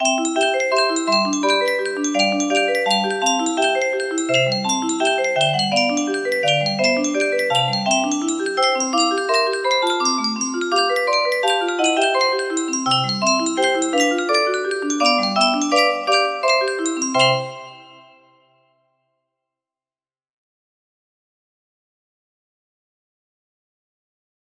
Santa Lucia music box melody
Traditional Neapolitan/Swedish